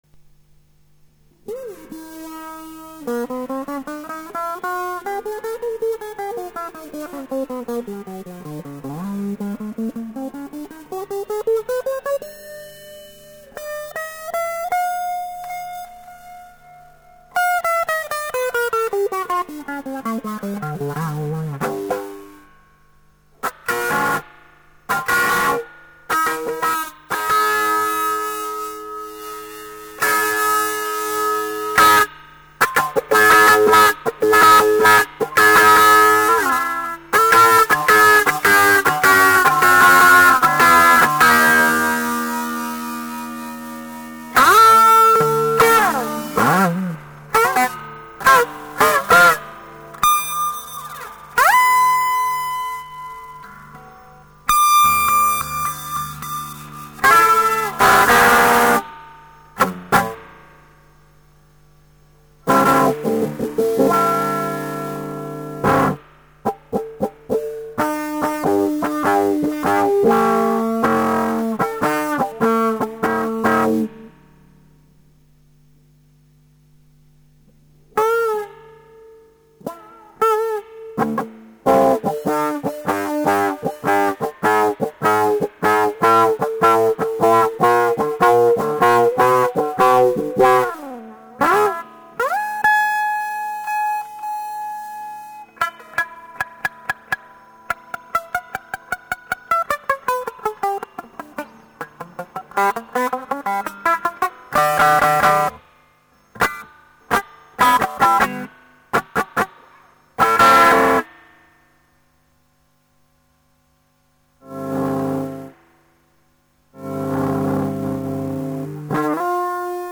えらぃクリーンな音やな、モーター廻せば振動で鳴っちゃうし